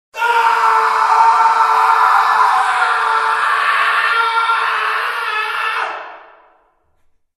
Звуки криков
Истеричный вопль мужчины